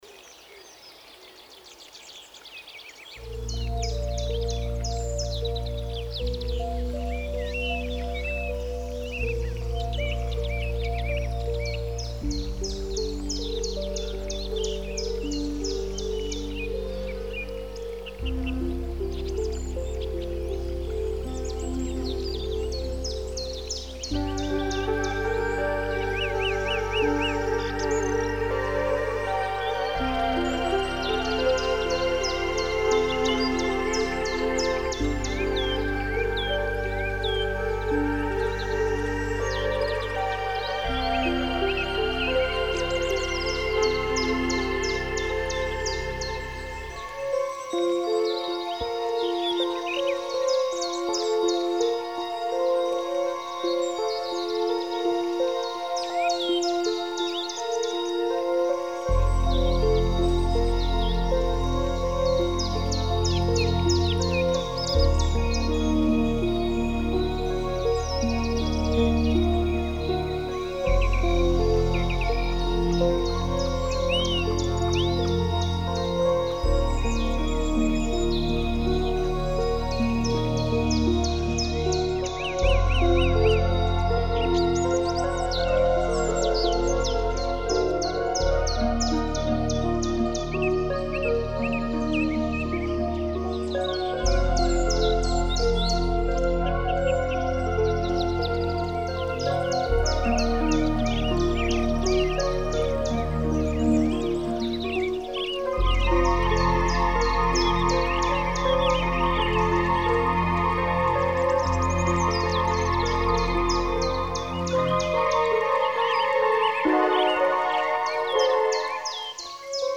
Медитативная музыка New age Нью эйдж